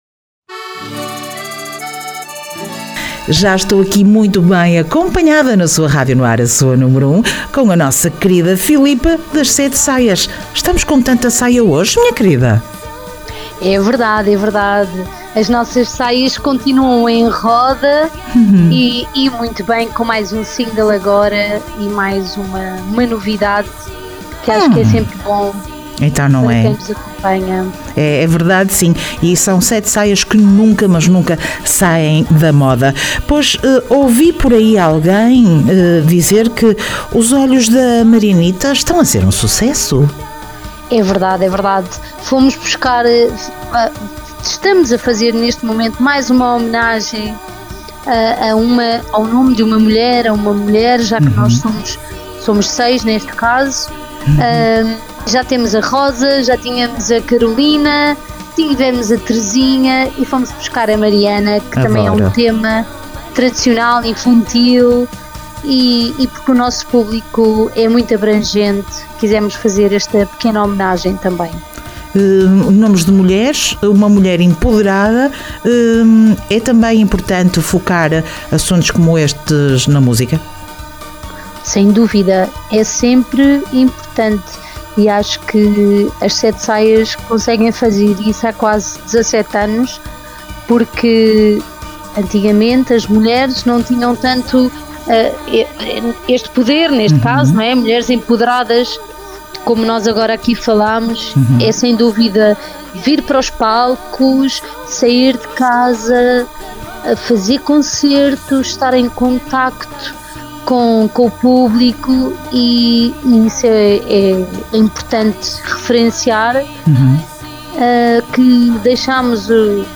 Entrevista 7 Saias